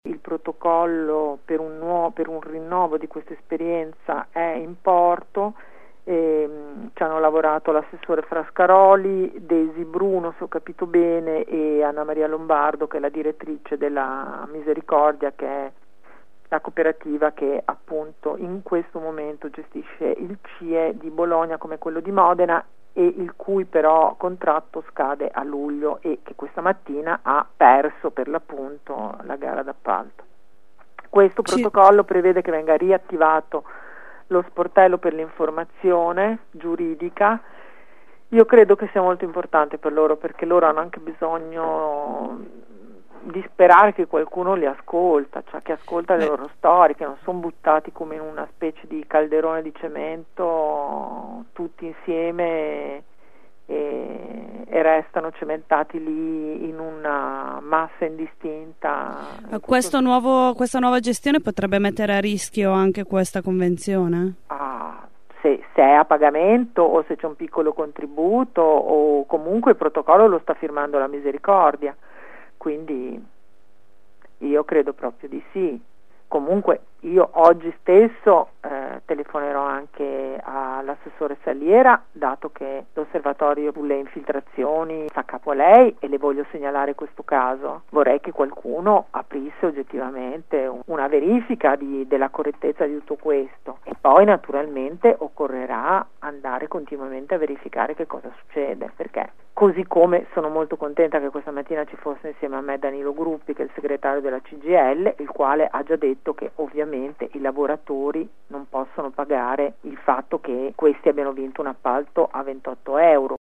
“Da una parte si firmano protocolli antimafia, dall’altra si emettono bandi che non possono essere vinti da aziende in regola, perché è matematicamente impossibile”, così la deputata del Pd Sandra Zampa critica ai nostri microfoni il comportamento della Prefettura, all’uscita dal centro di via Mattei per la campagna “LasciateCIE entrare“.